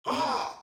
VO_LVL3_EVENT_Aha echec_03.ogg